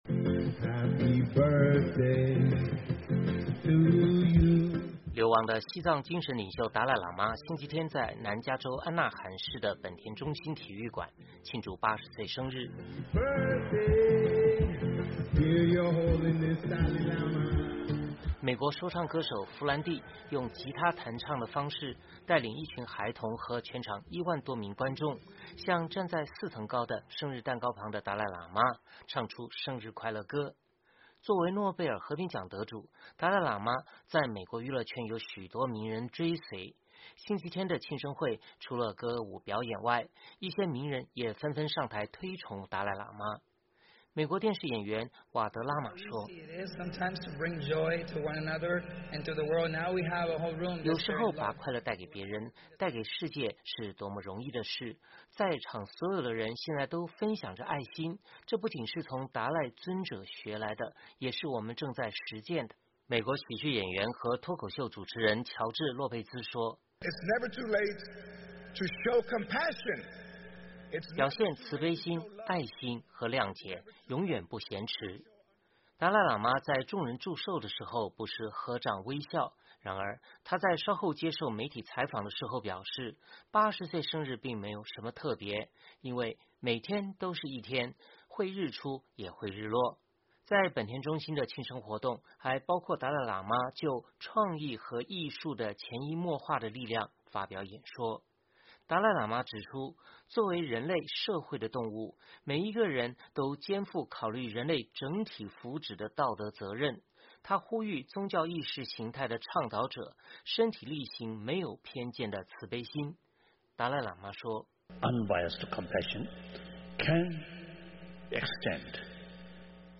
流亡的西藏精神领袖达赖喇嘛星期天在南加州安纳罕市的本田中心体育馆庆祝八十岁生日。美国说唱歌手弗兰帝用吉他弹唱的方式，带领一群孩童和全场一万多名观众，向站在四层高的生日蛋糕旁的达赖喇嘛唱出生日快乐歌。